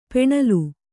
♪ peṇalu